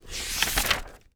TURN PAGE5-S.WAV